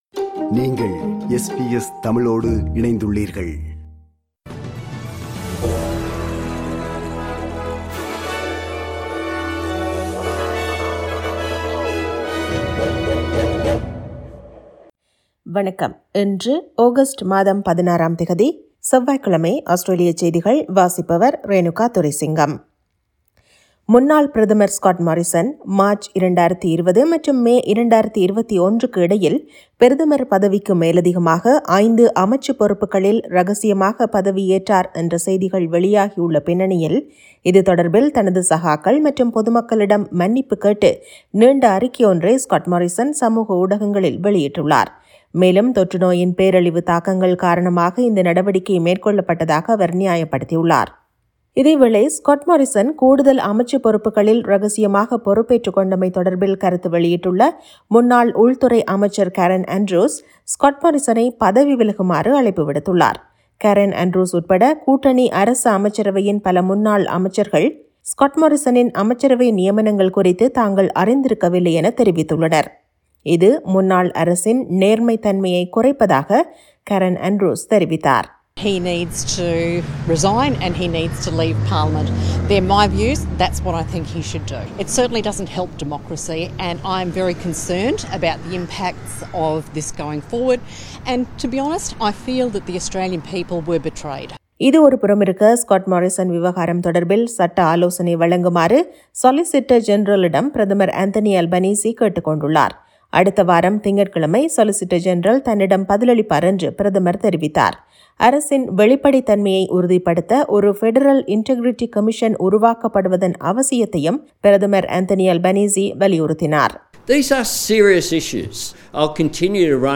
Australian news bulletin for Tuesday 16 Aug 2022.